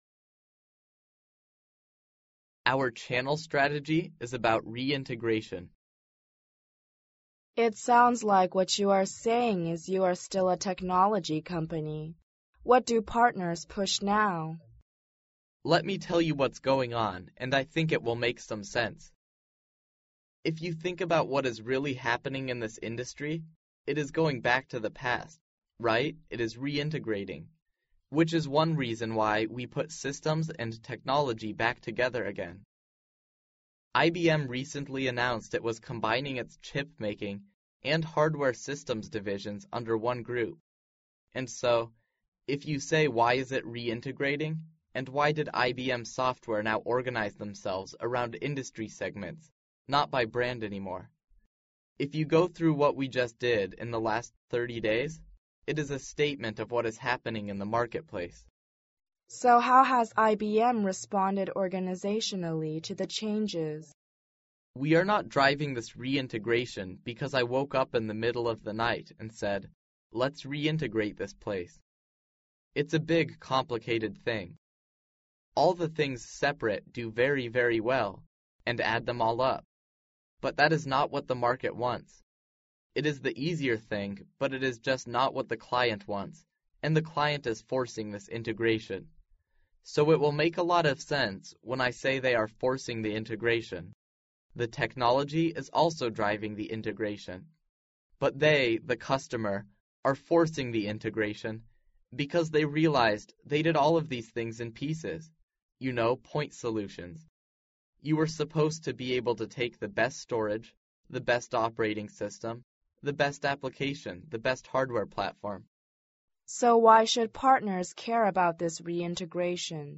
世界500强CEO访谈 第25期:IBM山姆彭明盛 营销战略和整合有关(1) 听力文件下载—在线英语听力室